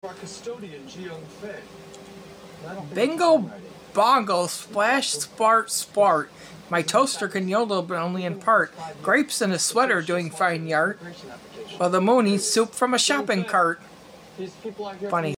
funny sound effects free download